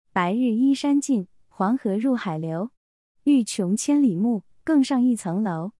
それぞれに中国語の朗読音声も付けておりますの韻を含んだリズム感を聞くことができます。
尚、第二句の「流(Liu)」と第四句の楼(Lou)が韻を踏んでいます。